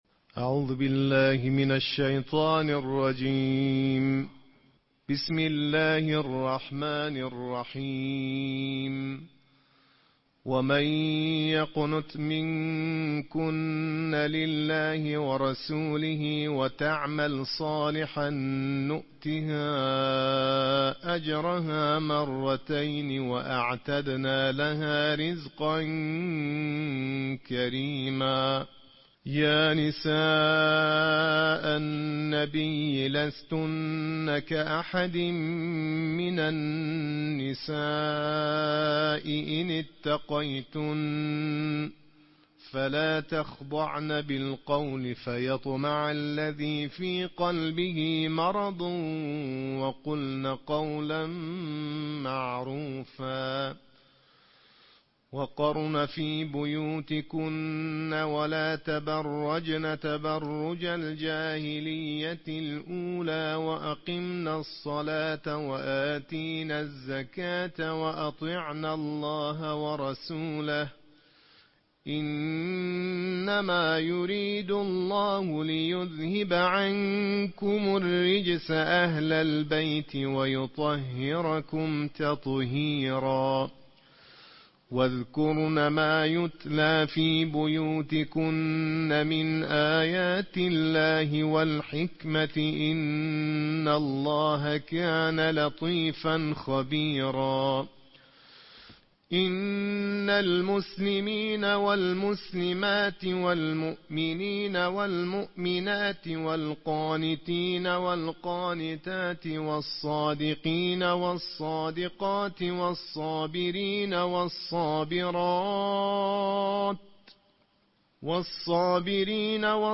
قرائت ترتیل جزء بیست‌ودوم قرآن با صدای قاریان بین‌المللی + صوت
نسخه باکیفیت تلاوت جزء بیست‌ودوم قرآن با صدای قاریان بین‌المللی